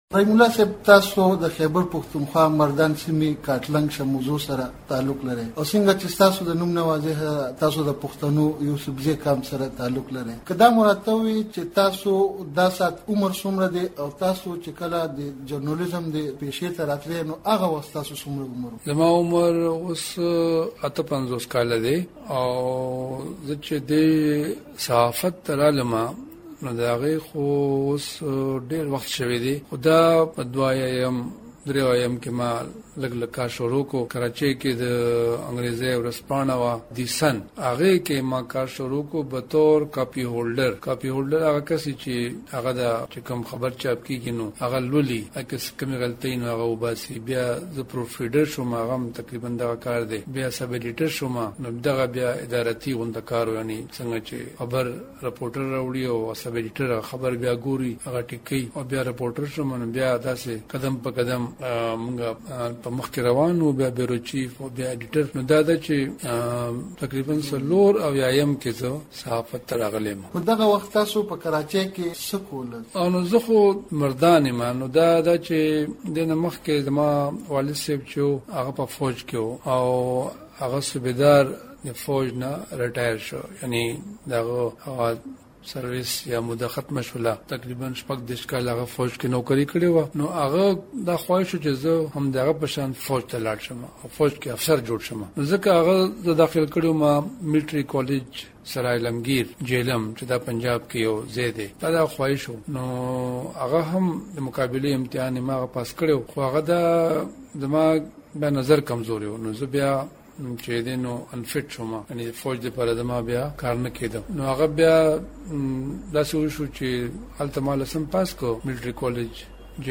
رحیم الله یوسفزي سره دمشال مرکه